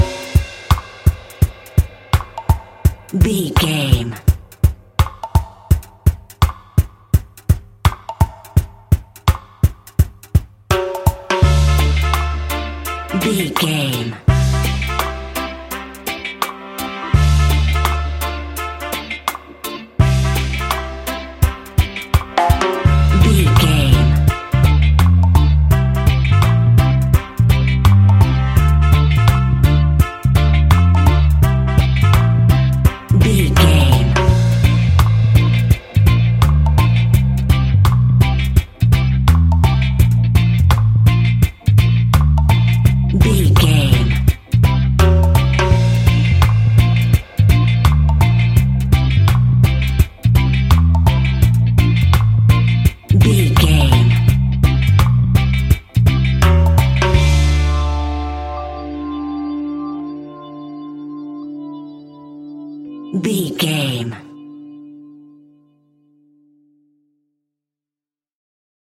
Classic reggae music with that skank bounce reggae feeling.
Aeolian/Minor
D
laid back
chilled
off beat
drums
skank guitar
hammond organ
percussion
horns